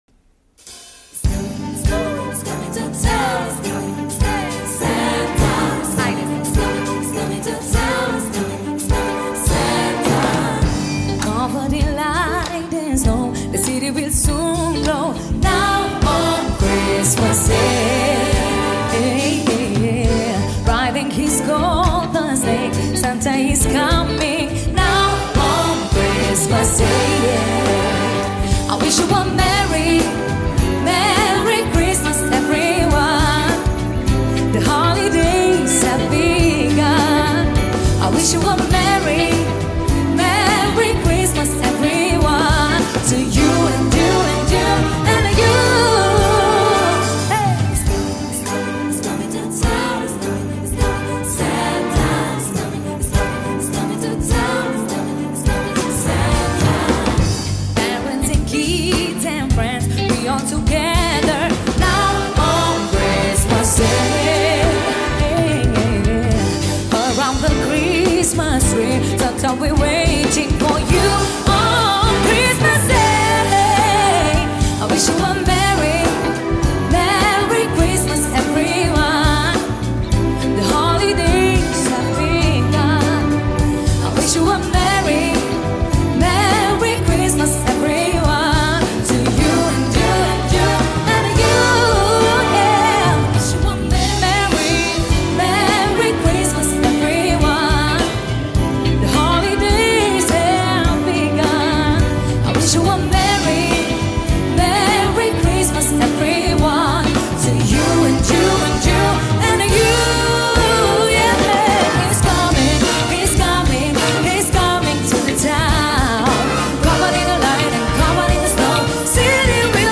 live La Opera Nationala
Data: 12.10.2024  Colinde Craciun Hits: 0